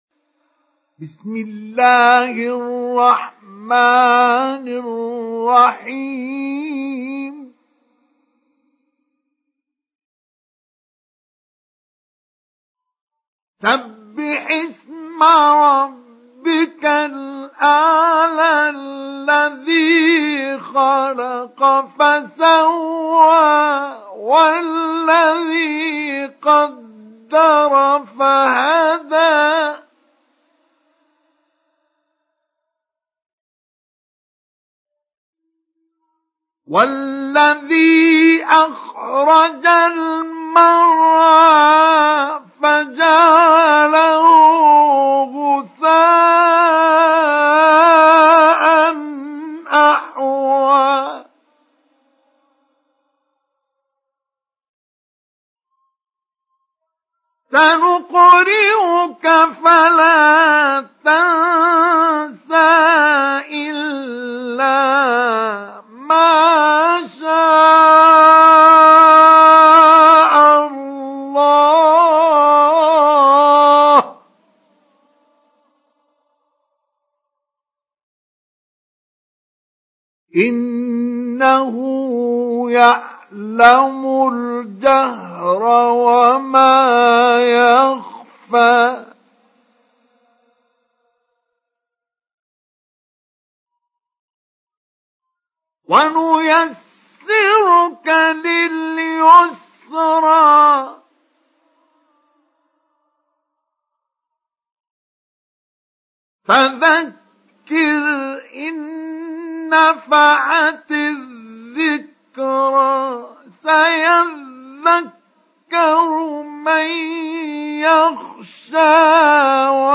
سُورَةُ الأَعۡلَىٰ بصوت الشيخ مصطفى اسماعيل